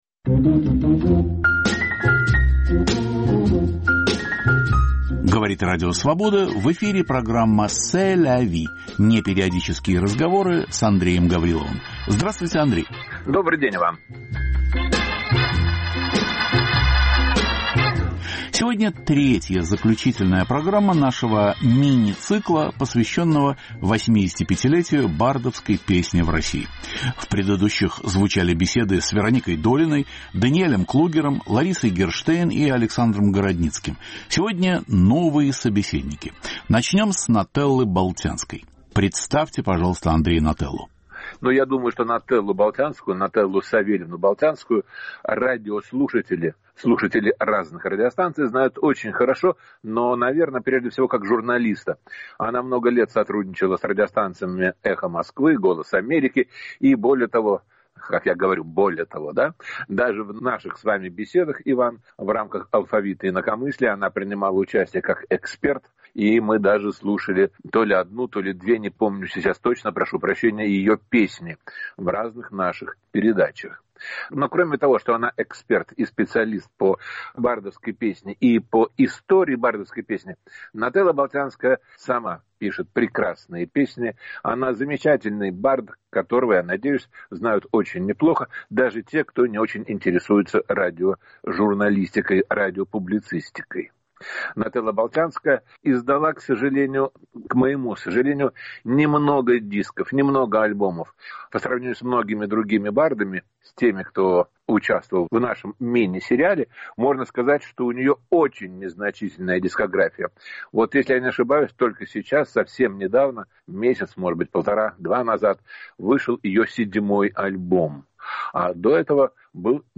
Два собеседника - Нателла Болтянская и Юлий Ким.